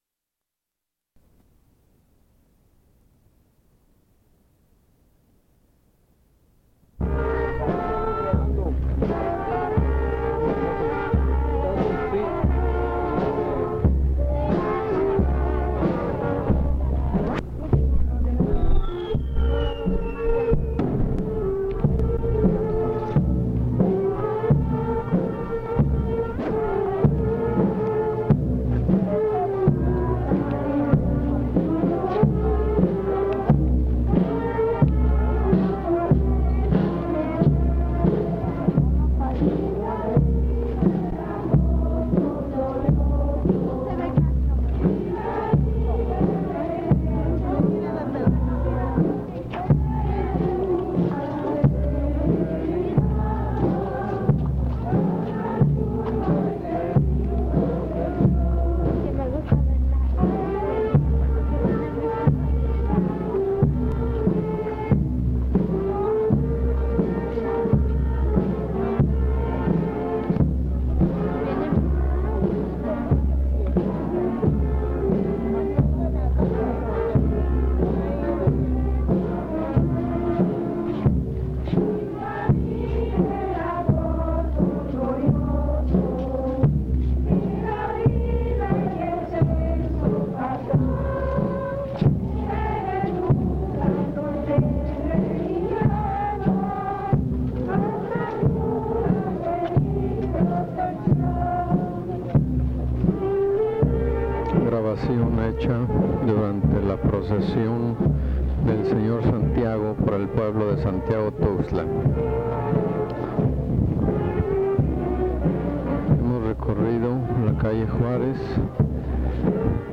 Santiago Tuxtla, Veracruz
Crónica Son jarocho